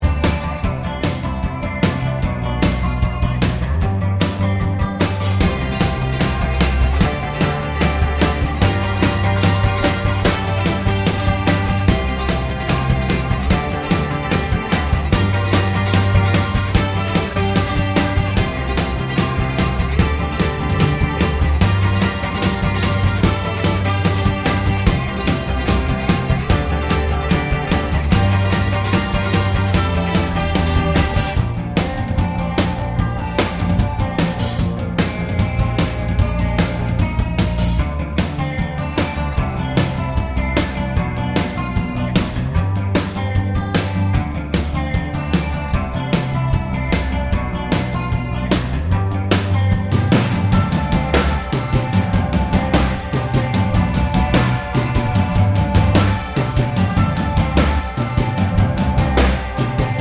no Backing Vocals Punk 3:16 Buy £1.50